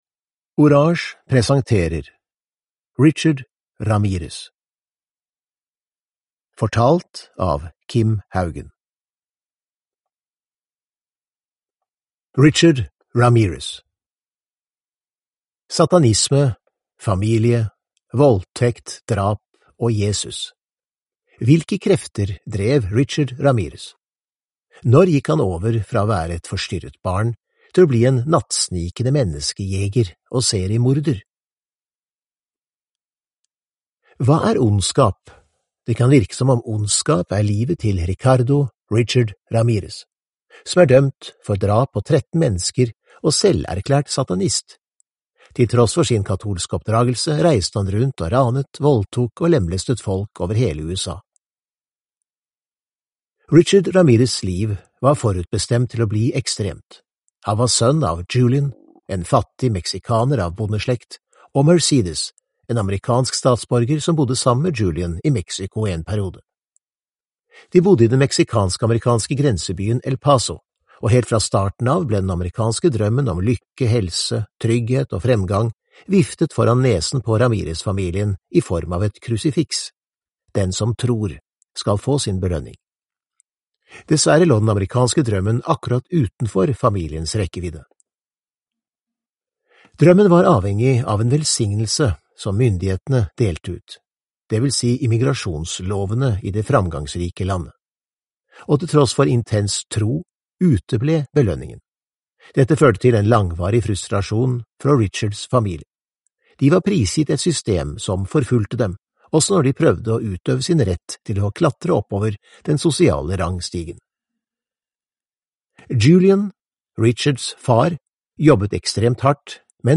Richard Ramirez (ljudbok) av Orage